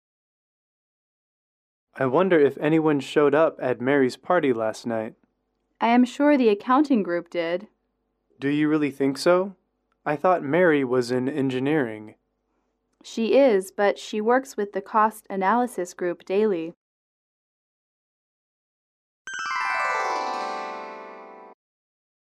英语主题情景短对话12-2：邀请同事参加宴会(MP3)